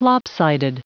Prononciation du mot lopsided en anglais (fichier audio)
Prononciation du mot : lopsided